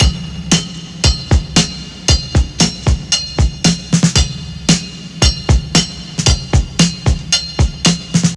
• 115 Bpm Drum Beat C Key.wav
Free drum loop - kick tuned to the C note.
115-bpm-drum-beat-c-key-az4.wav